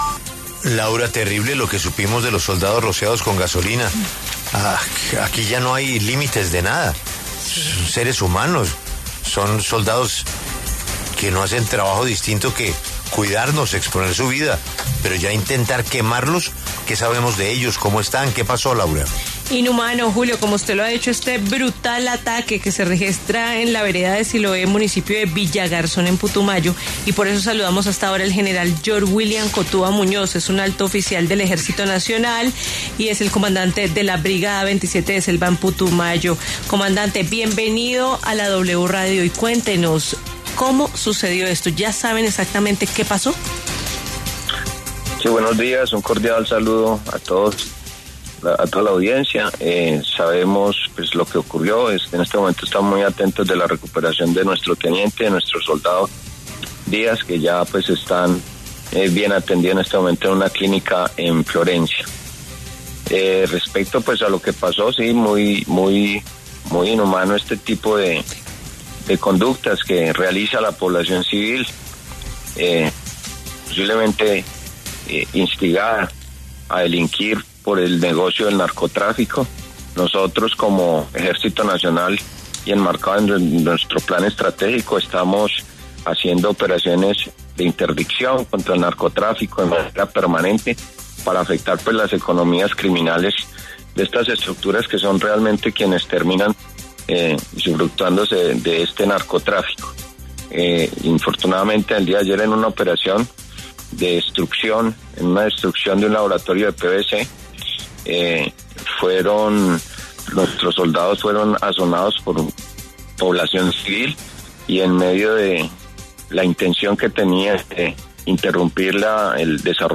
Para hablar sobre el tema, pasó por los micrófonos de La W el general Yor William Cotua, comandante de la Brigada 27 de Selva en Putumayo, quien detalló los hechos.